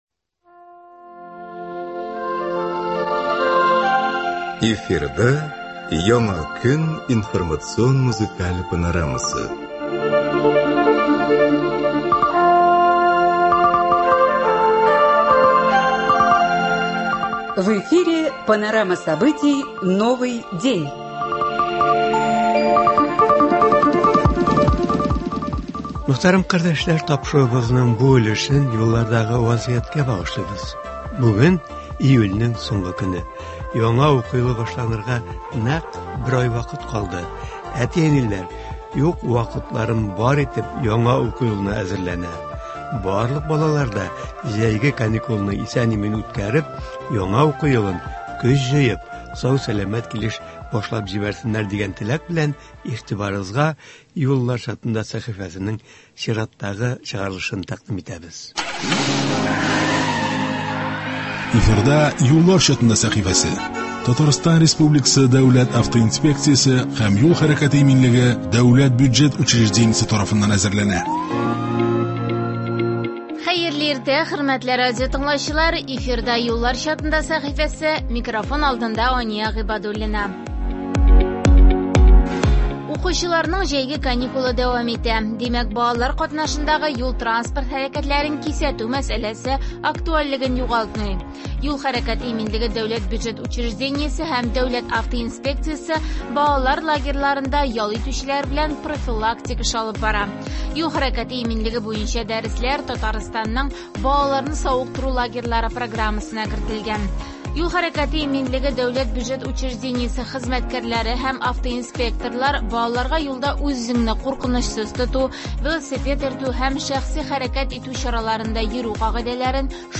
Бу сәхифәдә шушы кичәдә ясалган язмалар файдаланылыр.